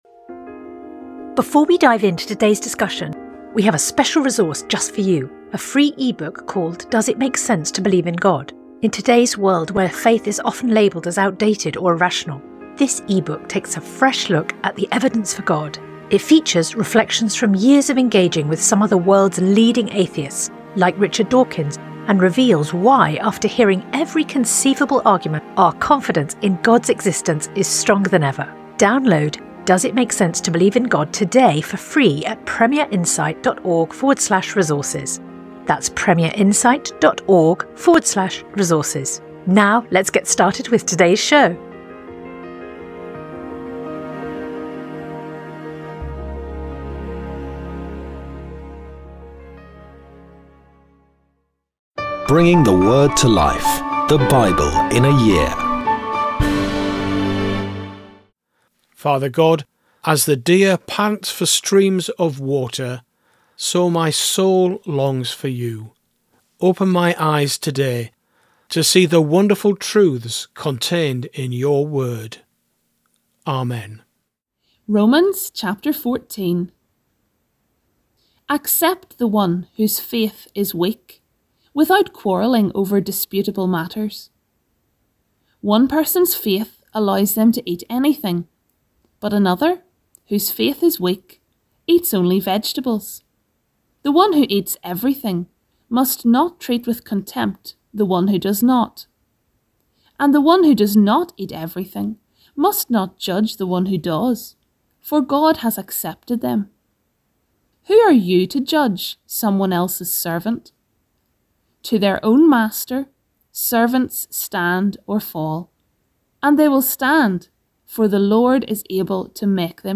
Today’s reading comes from Zechariah 1-4; Romans 14 Sponsored ad Sponsored ad